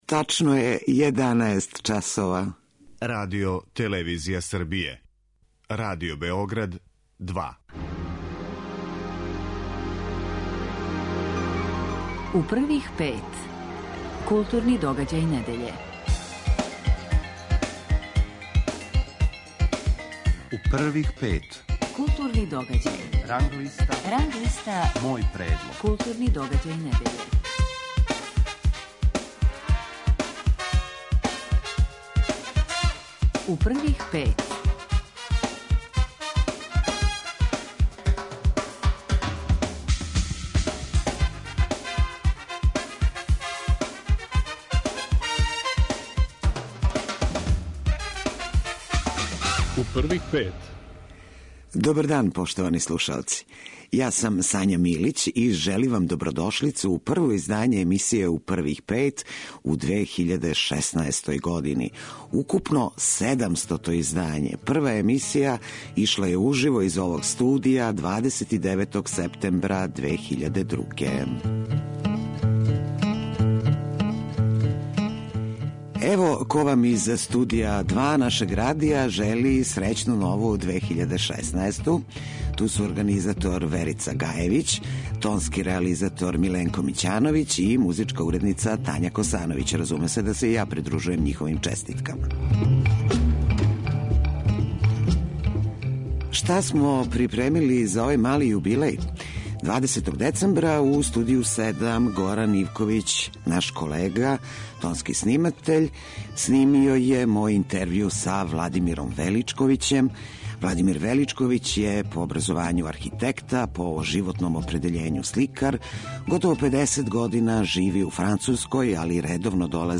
Гост 700. издања емисије 'У првих пет' биће сликар Владимир Величковић, уметник чија се дела чувају у седамдесет музеја у свету.